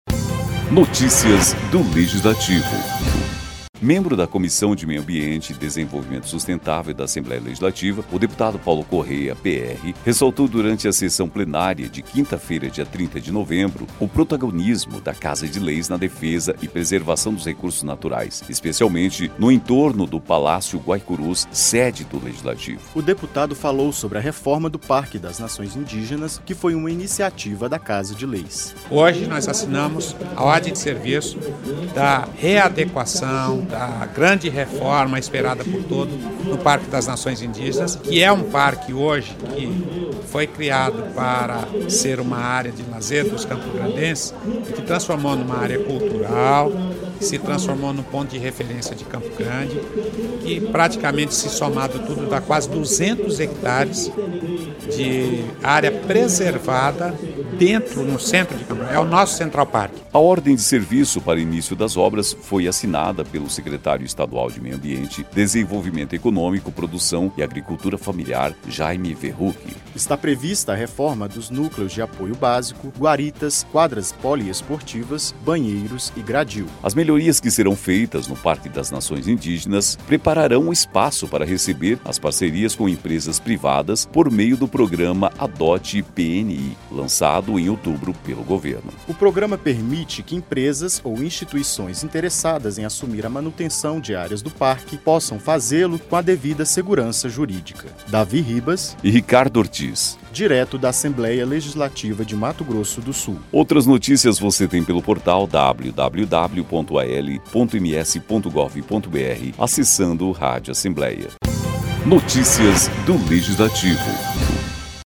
Membro da Comissão de Meio Ambiente e Desenvolvimento Sustentável da Assembleia Legislativa, o deputado Paulo Corrêa ressaltou, durante a sessão plenária desta quinta-feira (30/11), o protagonismo da Casa de Leis na defesa e preservação dos recursos naturais, especialmente no entorno do Palácio Guaicurus, sede do Legislativo.